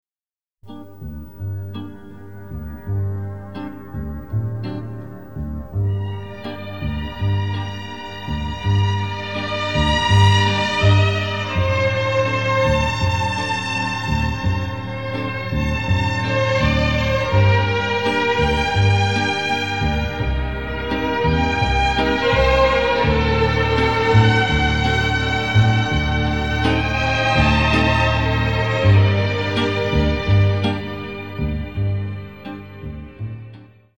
松下三电机卡座转录):
(已静噪)